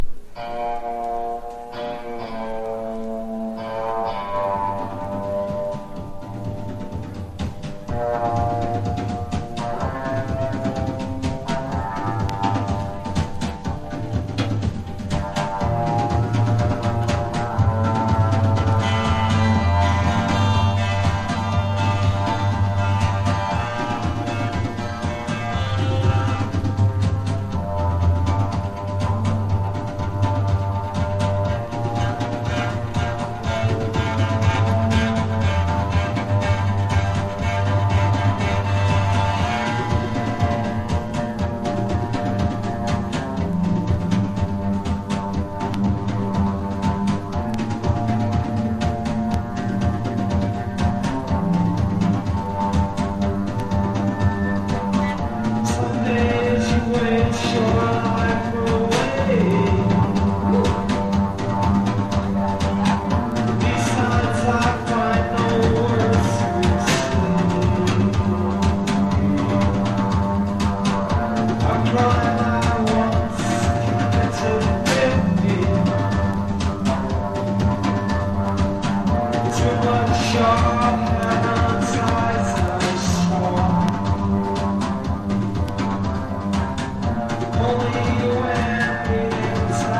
# POST PUNK
POPS# ELECTRO POP